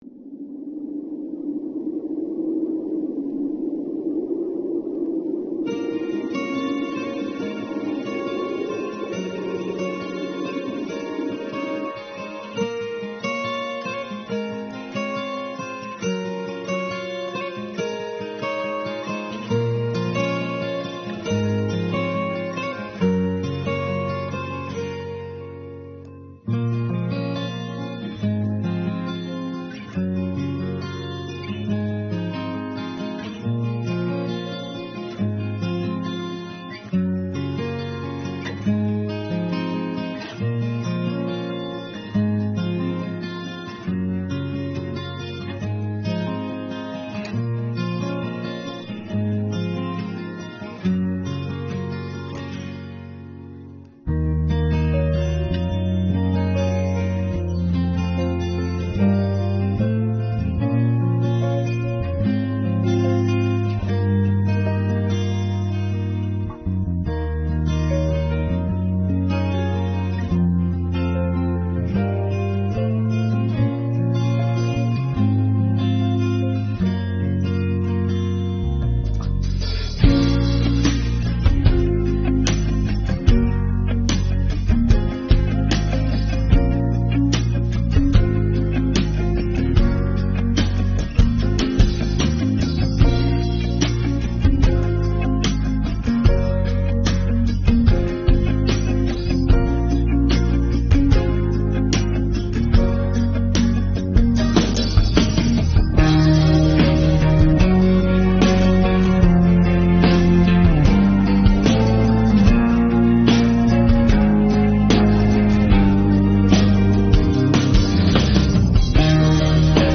Категория: Українські хіти караоке Описание